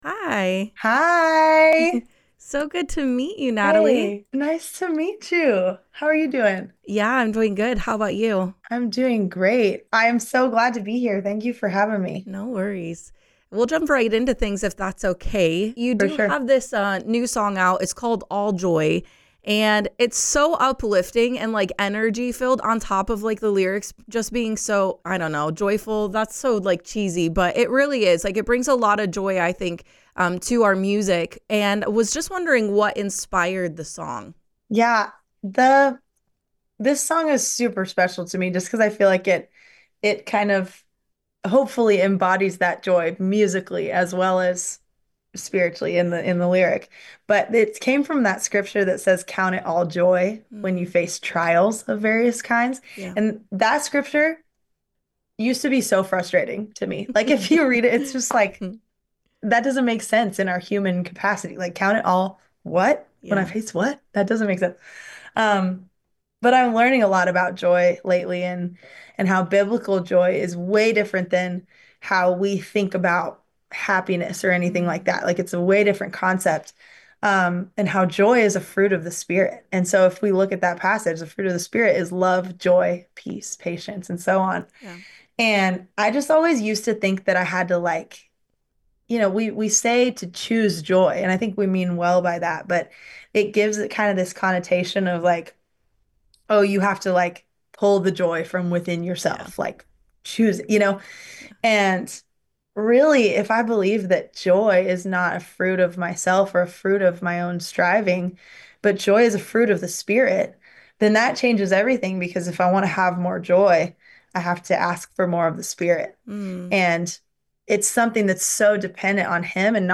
🎧 If you missed it, you can listen to the full interview below!